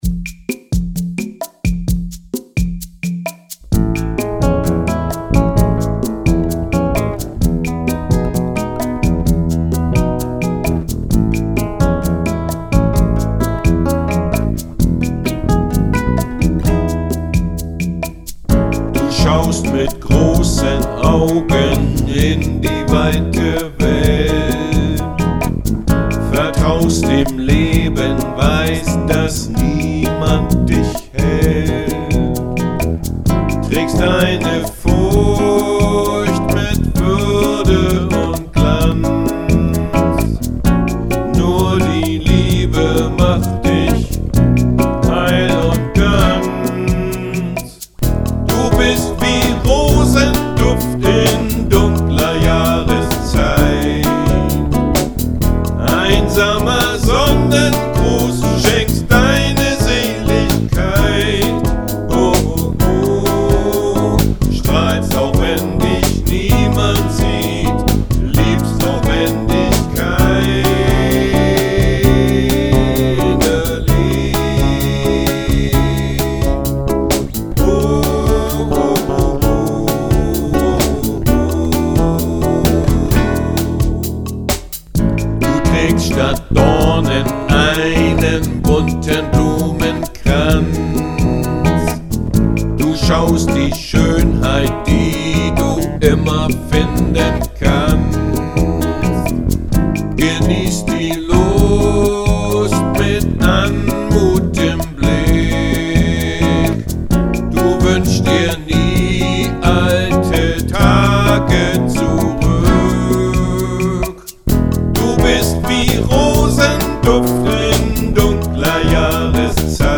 Die Audio-Tracks sind mit allen Instrumenten (und Vocals) von mir allein eingespielt worden (home-recording).
Seit Frühjahr 2012 benutze eine etwas bessere Aufnahmetechnik (mit einem externen Audio-Interface und einem besseren Mikrofon).